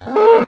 cowhurt1